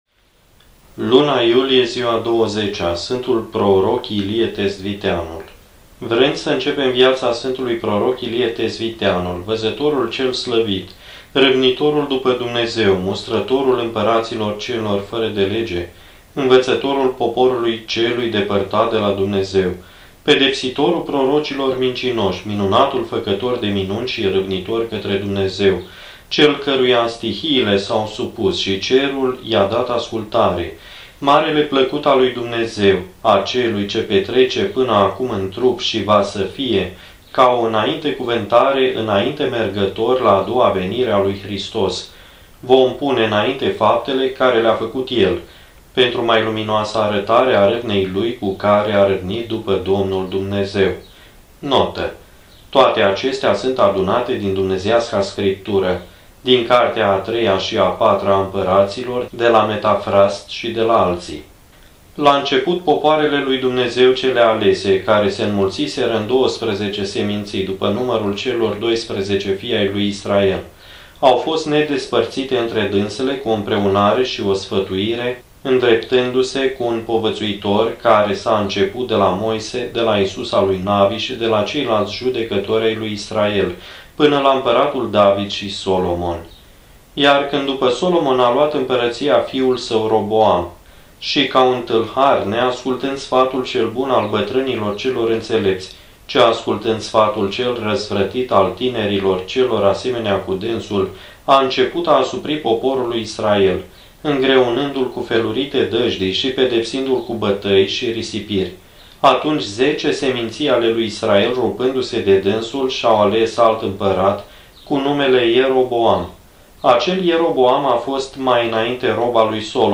Index of /carti audio/vietile sfintilor/07. Vietile Sfintilor pe Iulie/20.